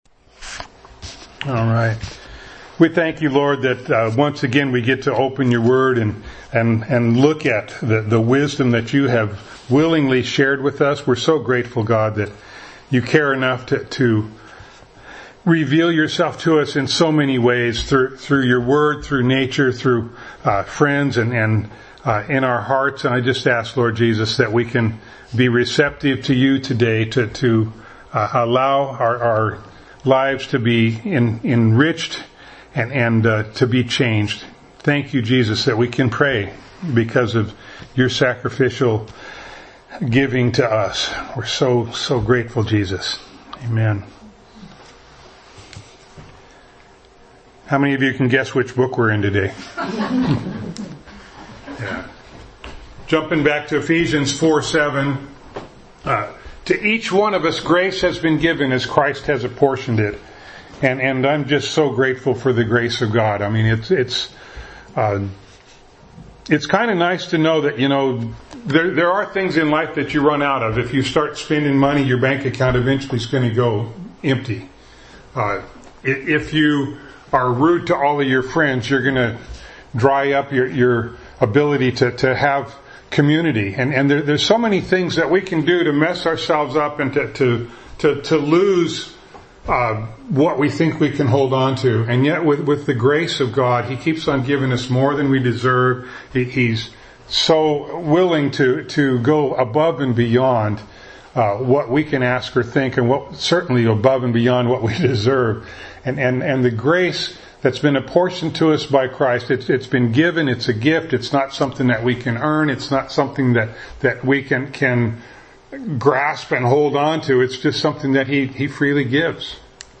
Ephesians 4:14 Service Type: Sunday Morning Bible Text